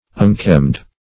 unkemmed - definition of unkemmed - synonyms, pronunciation, spelling from Free Dictionary Search Result for " unkemmed" : The Collaborative International Dictionary of English v.0.48: Unkemmed \Un*kemmed"\, a. Unkempt.
unkemmed.mp3